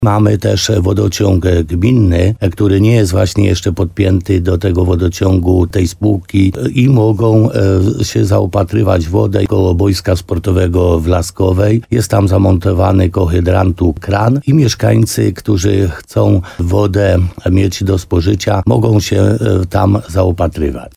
Mieszkańcy centrum Laskowej mają problem ze skażeniem wody po ulewnych deszczach, która przeszły nad miejscowością w ubiegłym tygodniu – mówił w programie Słowo za słowo na antenie RDN Nowy Sącz Piotr Stach, wójt gminy Laskowa.